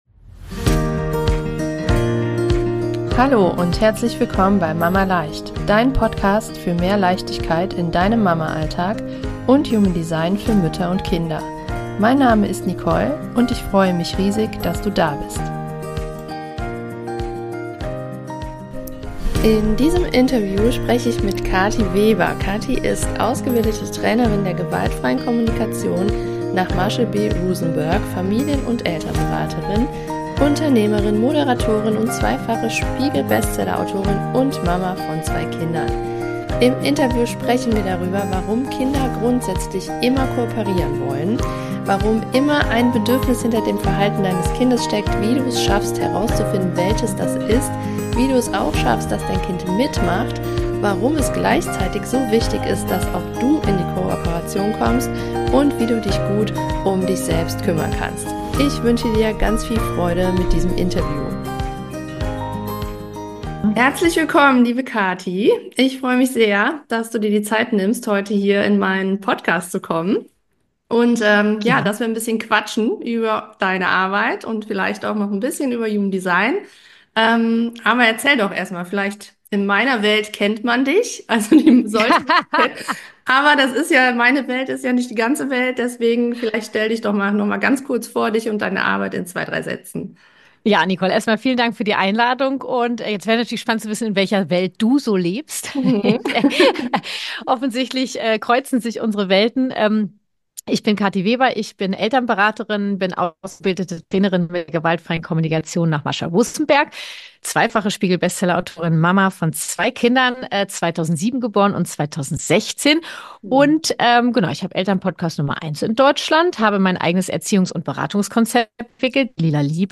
076 - Kooperation beginnt bei dir - Interview